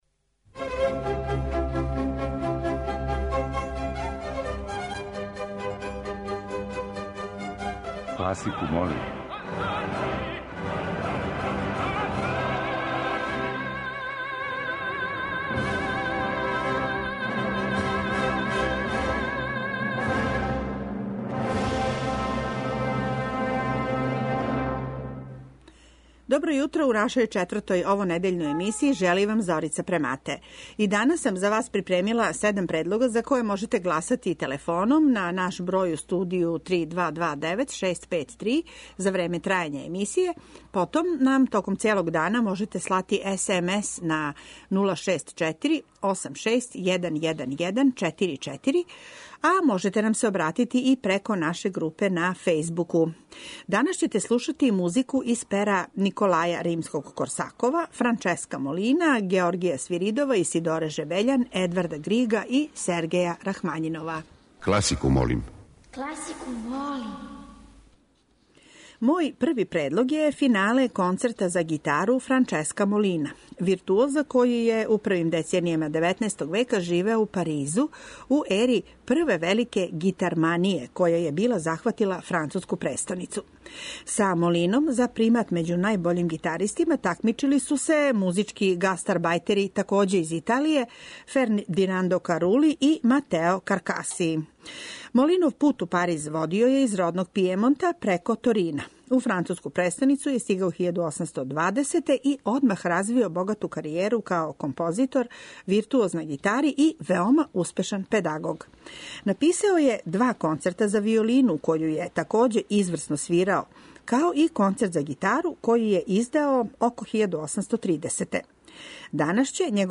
Сваког дана по један од предлога биће и музичка прича о санкању, а из пера Леополда Моцарта, Сергеја Прокофјева, Фредерика Дилијуса и Георгија Свиридова.